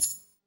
Coin Collect
A bright, satisfying coin collection chime with a sparkling upward pitch sweep
coin-collect.mp3